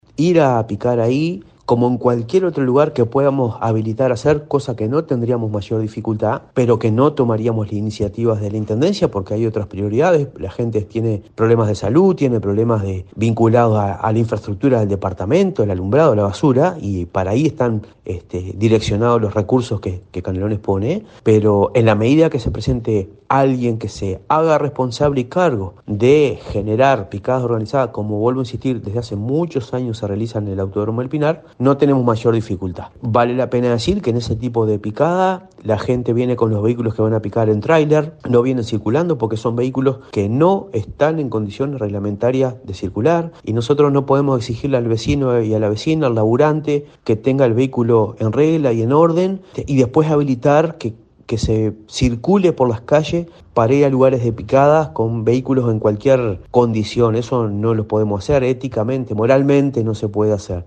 entrevistó al Director General de Tránsito y Transporte de Canelones, Marcelo Metediera, sobre las picadas de automóviles y motos en El Pinar.